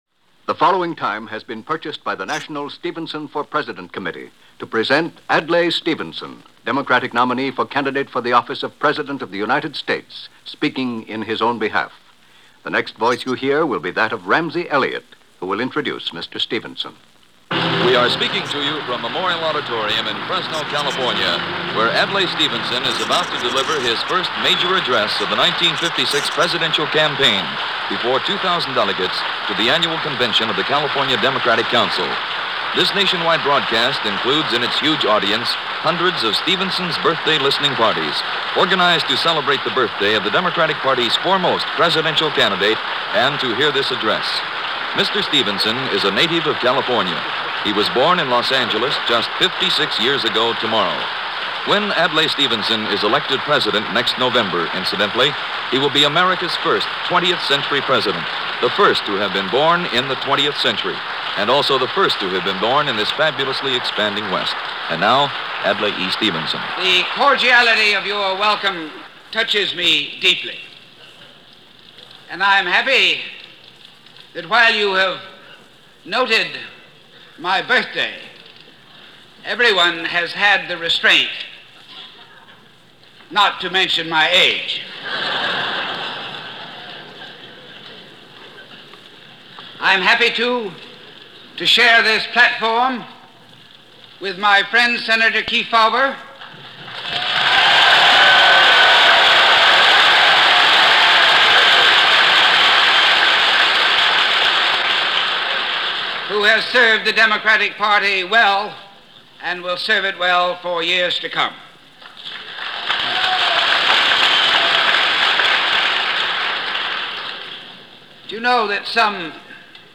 As a reminder of his greatness, and as tribute on his birthday, here is his campaign kickoff speech, delivered on February 4th, 1956 to 2,000 delegates of the annual Convention of the California Democratic Council in Fresno . The first major address of the 1956 campaign.
Adlai-Stevenson-Address-Feb.-4-1956.mp3